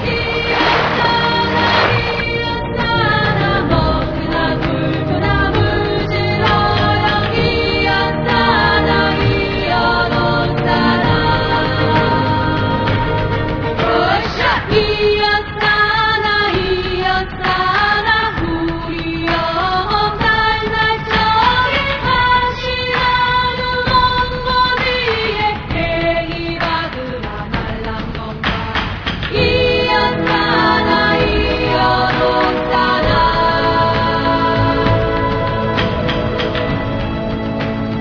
Opening 曲